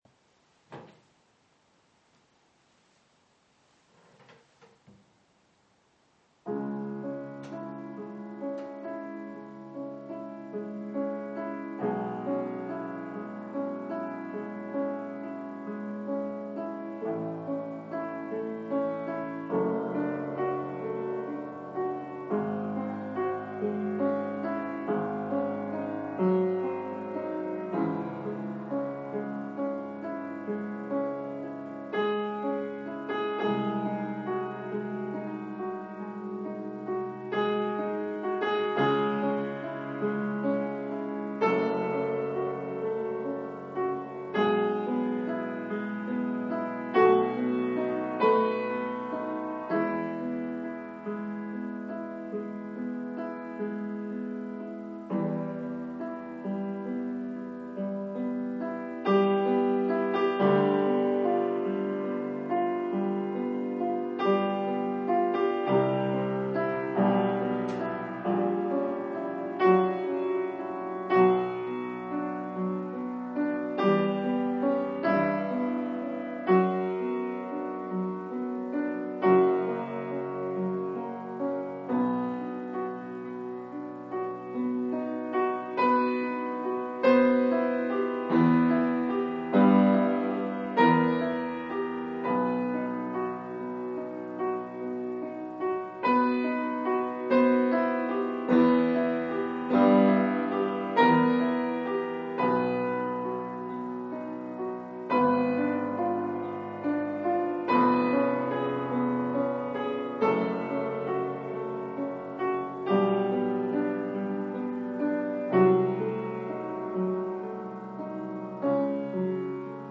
Sans faute, mais rythmique non-régulière et mélodie pas inspirée.
8'31, 2 Mo, mp3 32 kbps (qualité de micro médiocre)